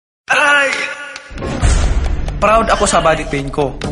alaxanfrcommercialfeaturingmannypacquiao-3djma76ttzg_cutted.mp3